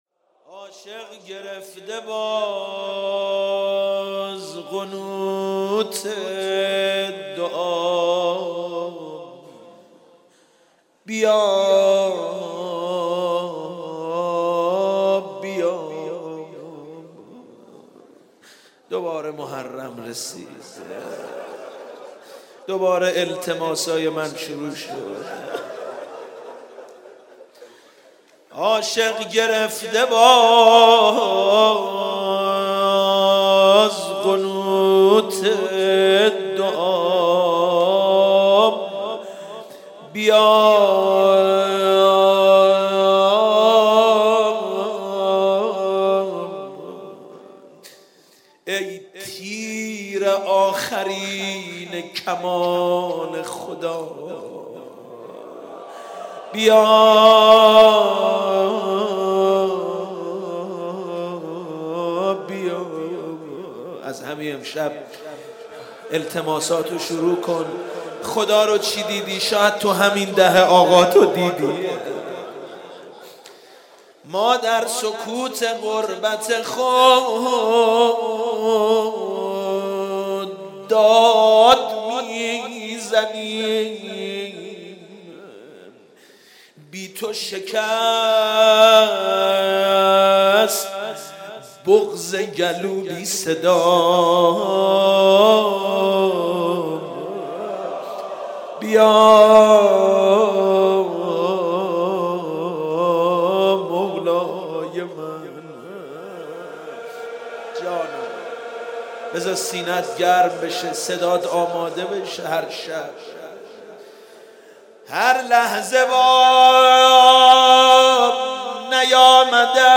در شب اول محرم 93
صحبت